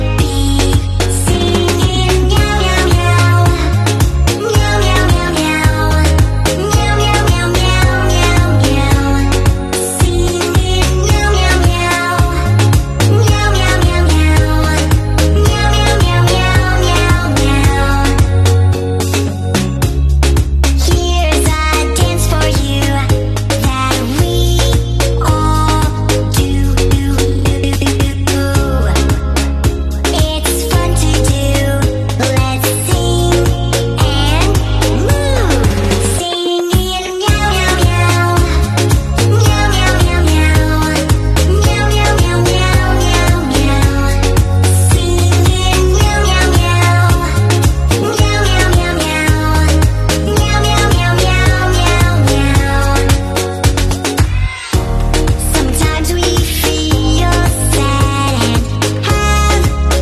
Meow!! 🦄🌈 sound effects free download